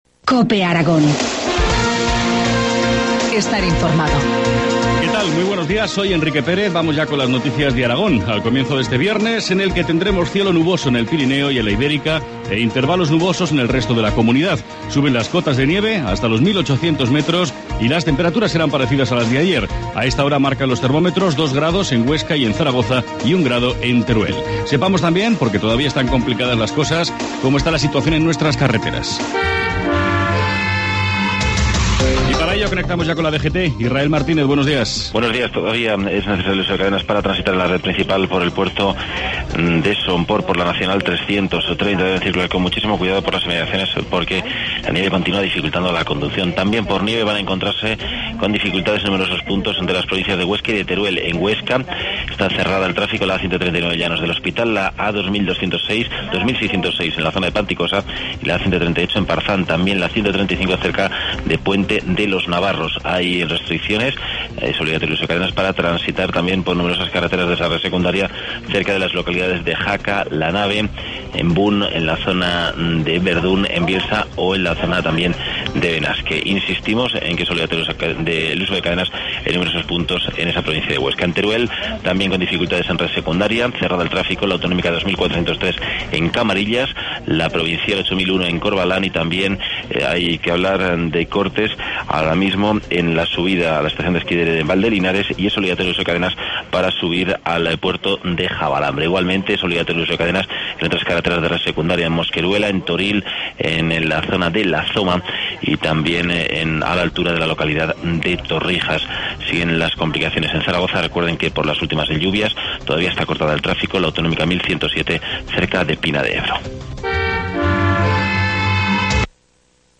Informativo matinal, viernes 25 de enero, 7.25 horas